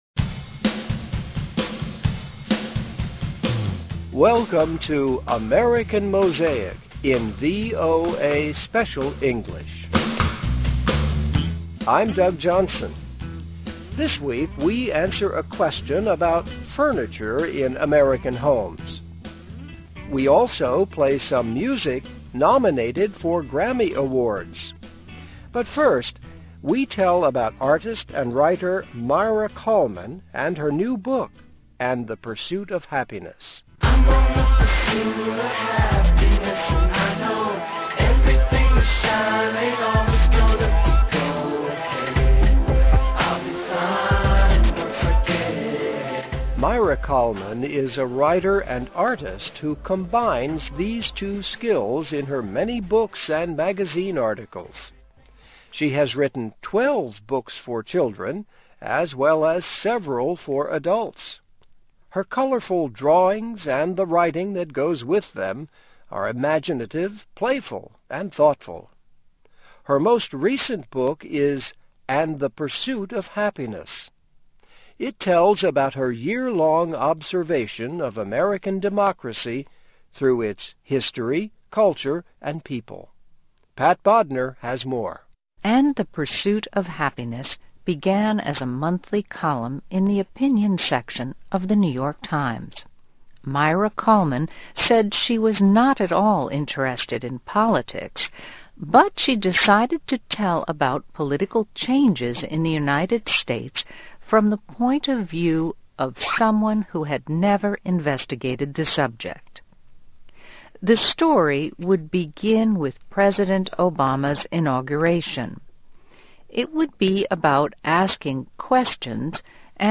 Welcome to AMERICAN MOSAIC in VOA Special English.